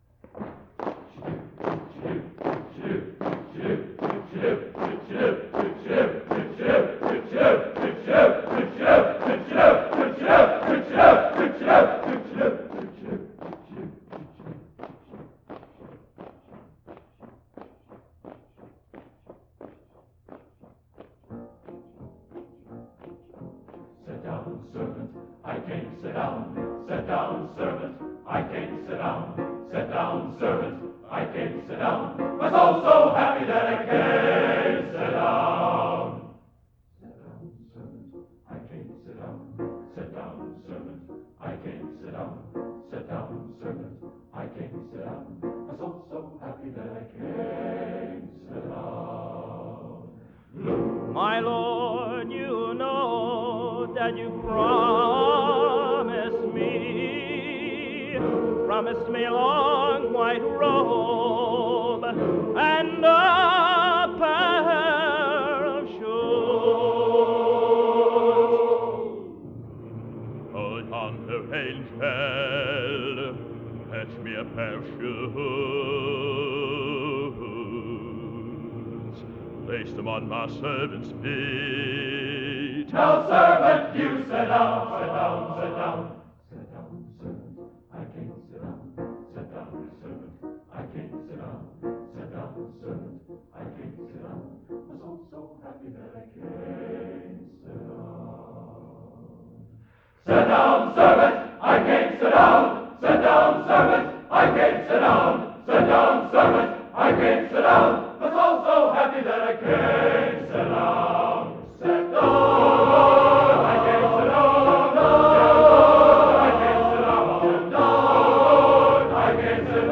Genre: Spiritual | Type: End of Season